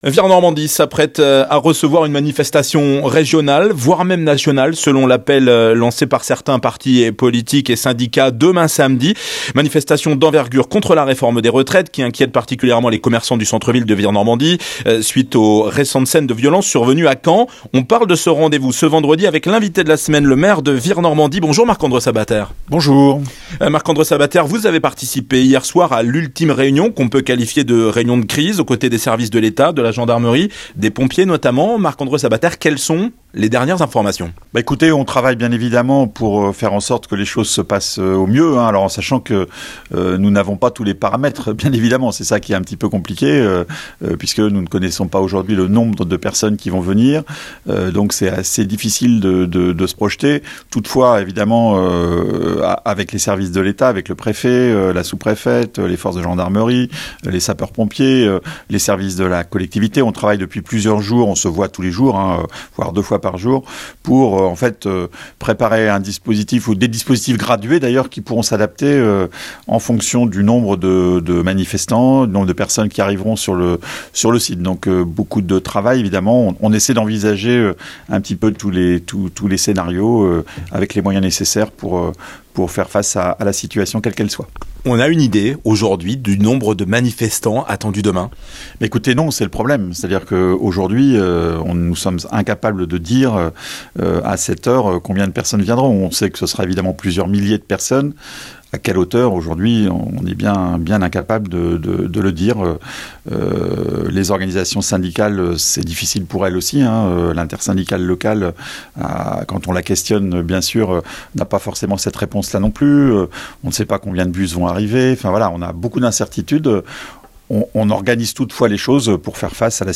Marc Andreu Sabater , maire de Vire Normandie et vice-président du Calvados, Invité de la semaine sur RadioVFM